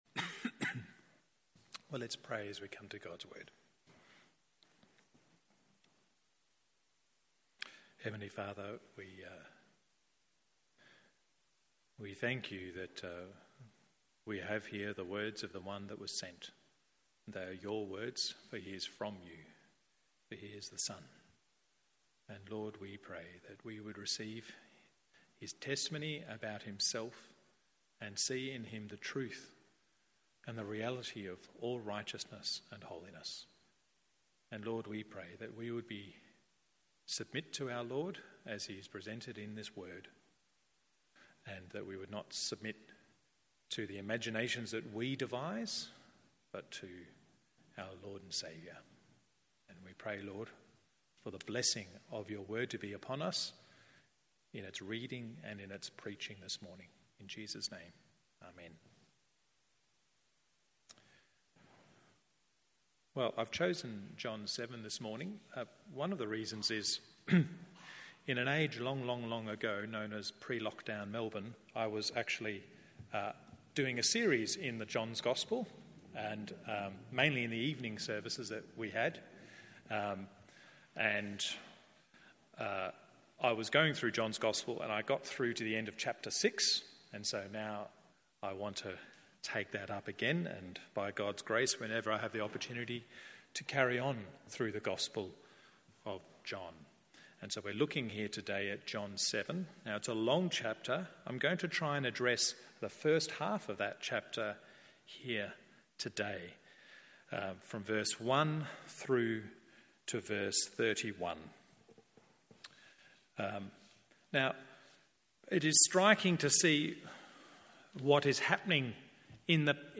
MORNING SERVICE John 7…